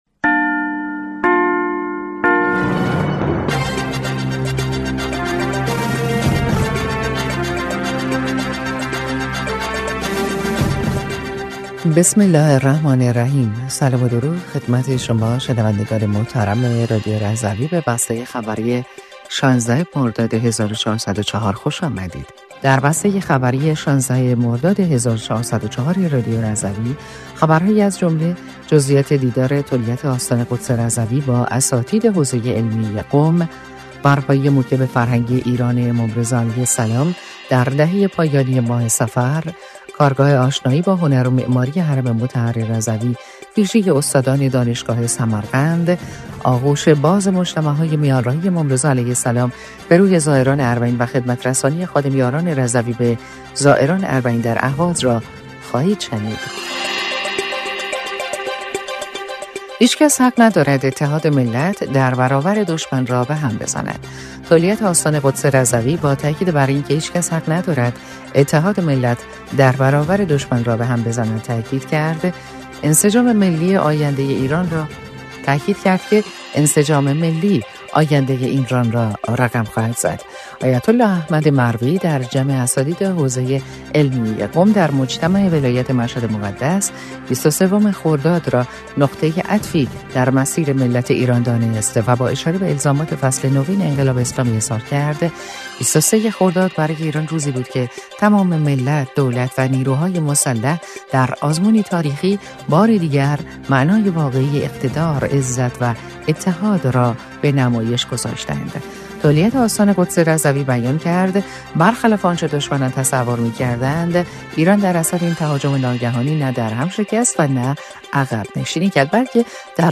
بسته خبری ۱۶ مرداد ۱۴۰۴ رادیو رضوی؛